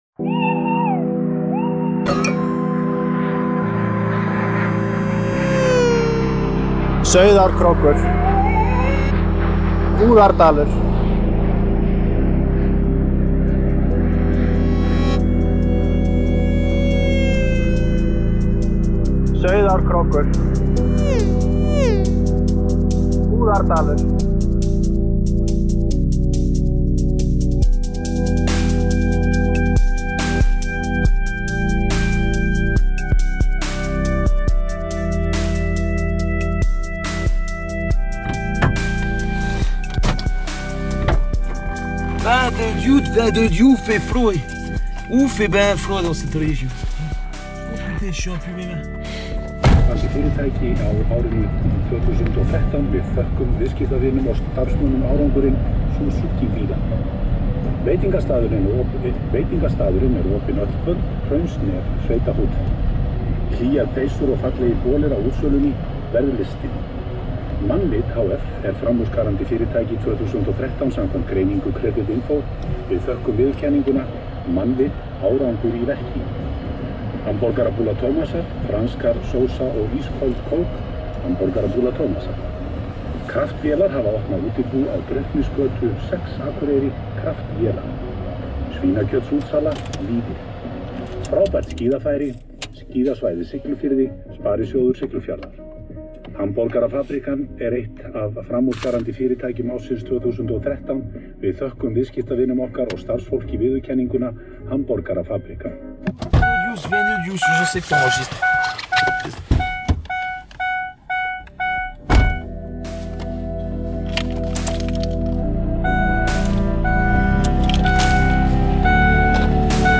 L’ambiance du road trip